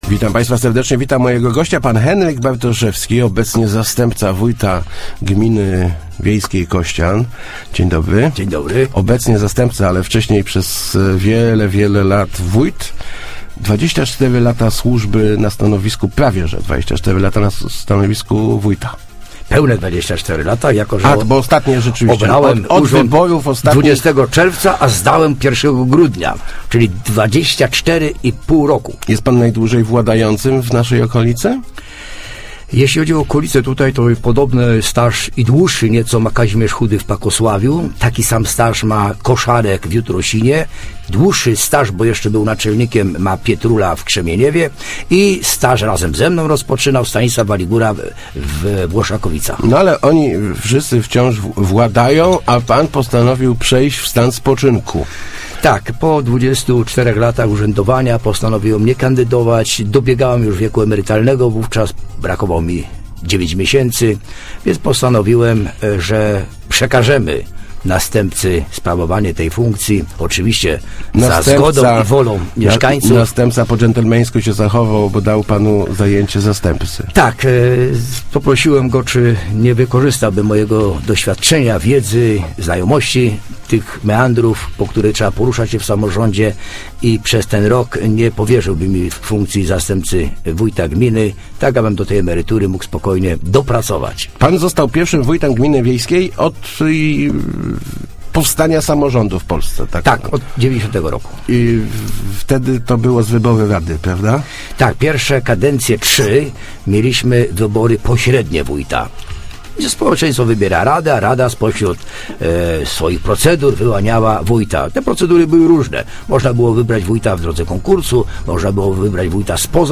Najwi�kszym osi�gni�ciem tych 24 lat by�o wyrównanie ró�nic cywlizacyjnych mi�dzy naszymi miejscowo�ciami a terenami miejskimi -mówi� w Rozmowach Elki Henryk Bartoszewski, wieloletni wójt, obecnie zast�pca wójta gminy Ko�cian.